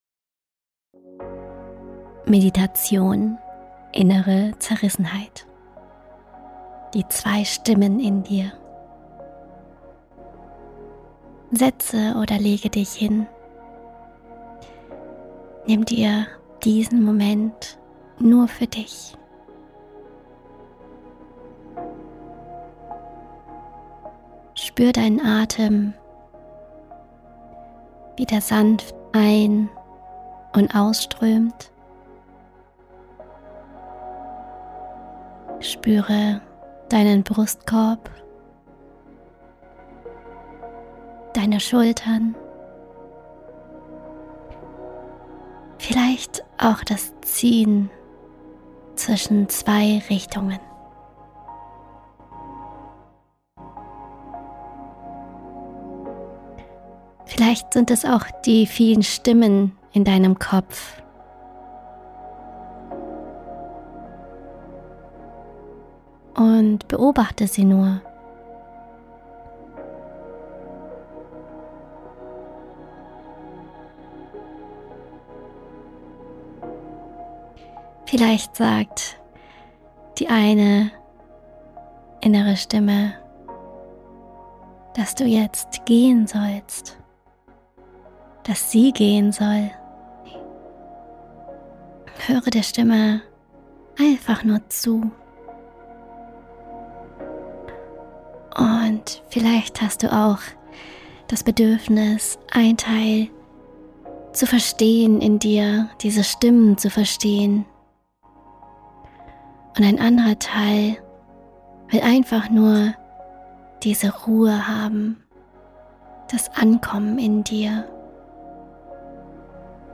Begleitende Meditation zu Folge 2